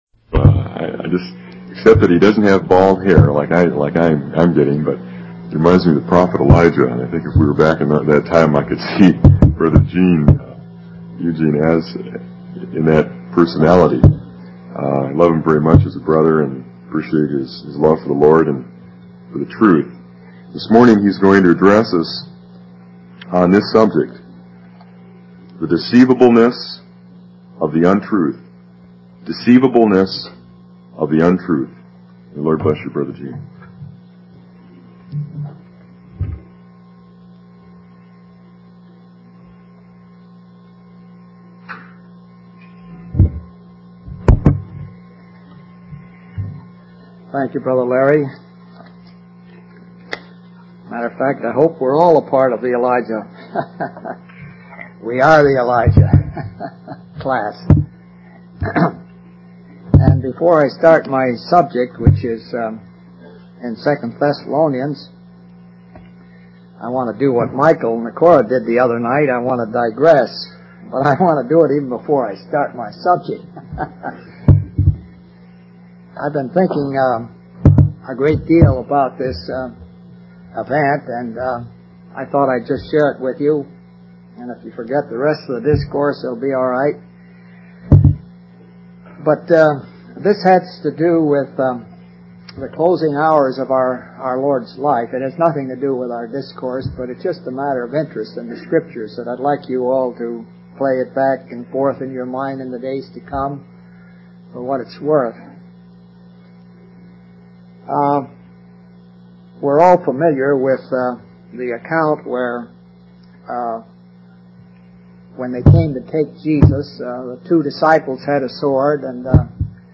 From Type: "Discourse"
Jackson Convention 1984